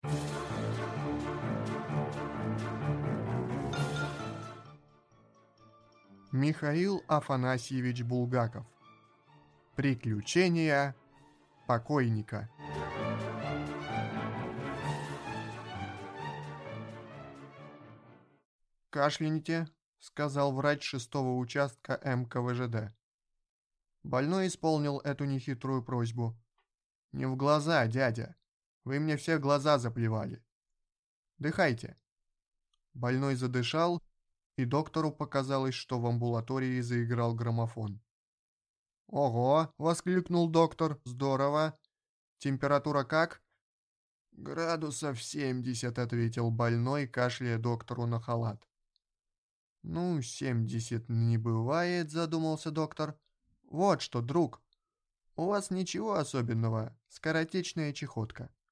Аудиокнига Приключения покойника | Библиотека аудиокниг